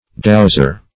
Dowser \Dows"er\, n.